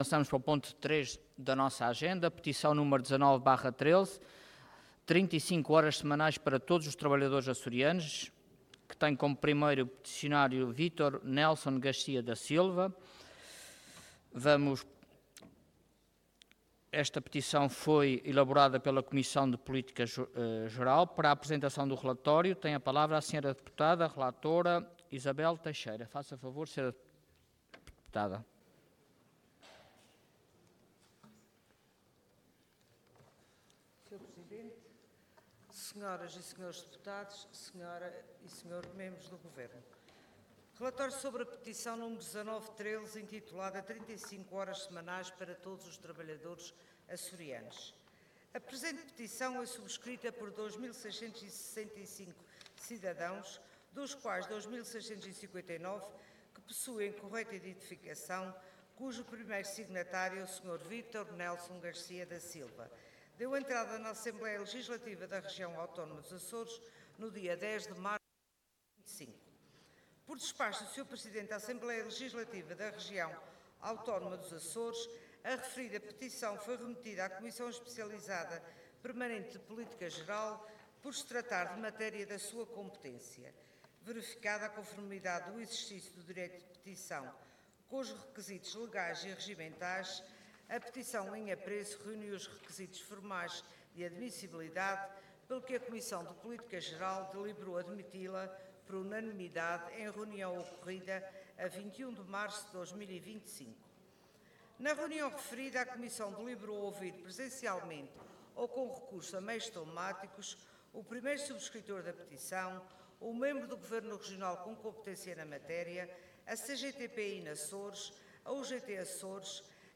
Intervenção